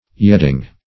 Search Result for " yedding" : The Collaborative International Dictionary of English v.0.48: Yedding \Yed"ding\, n. [AS. geddung, gidding, giedding, from gieddian, giddian, to sing, speak.]